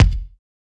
BEAT KICK 03.WAV